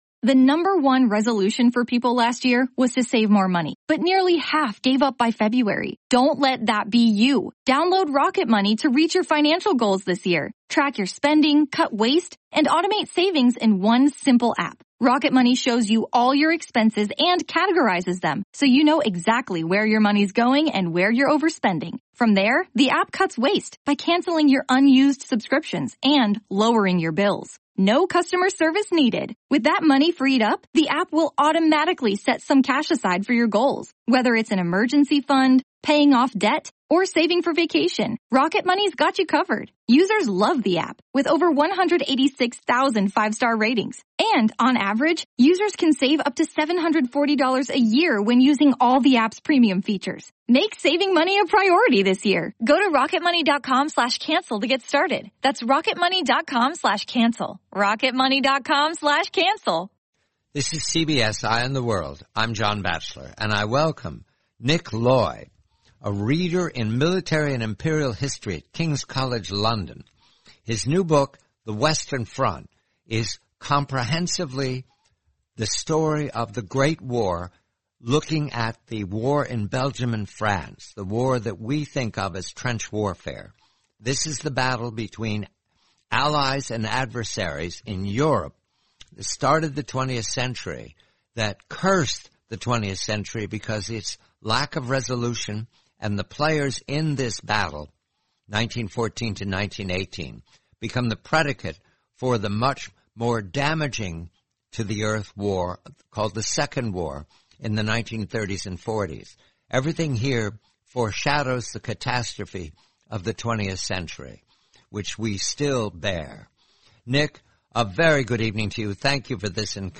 The complete, eighty-minute interview.